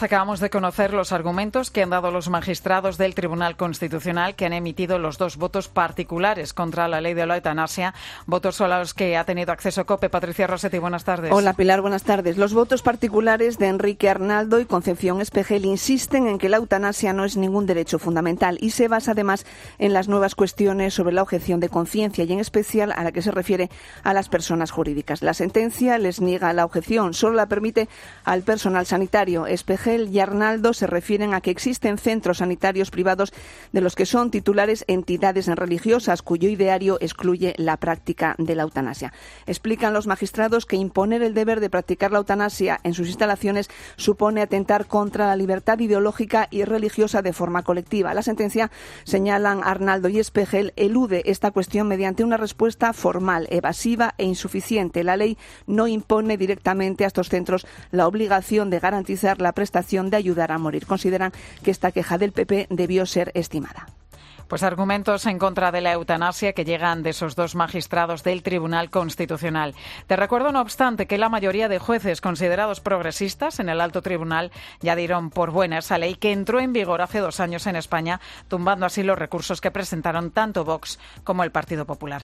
detalla los argumentos de los magistrados que se oponen a la Ley de Eutanasia